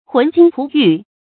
浑金璞玉 hún jīn pú yù
浑金璞玉发音